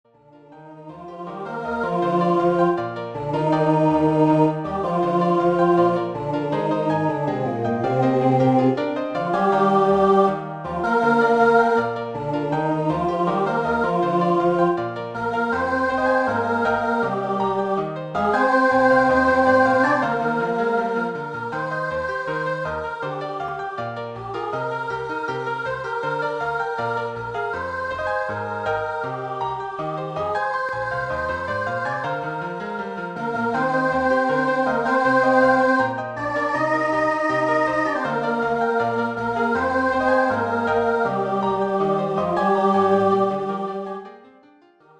• Choral